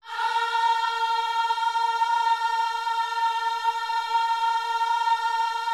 OHS A#4E.wav